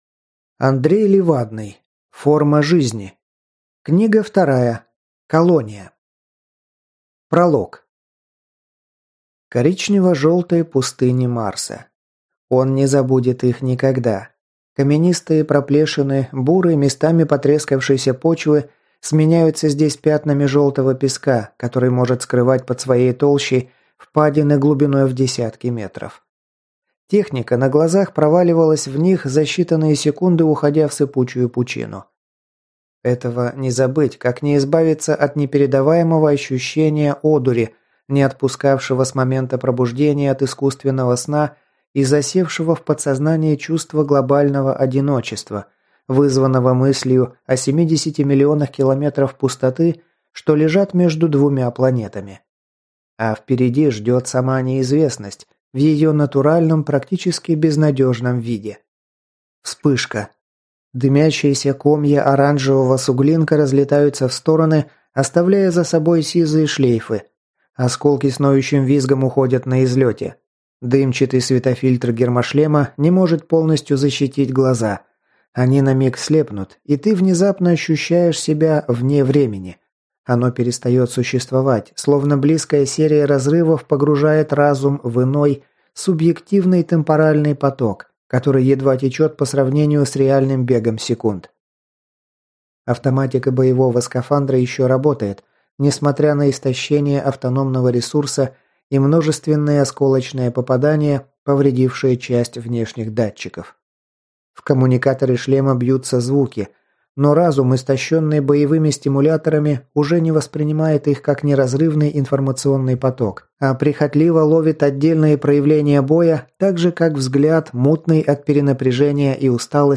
Аудиокнига Колония | Библиотека аудиокниг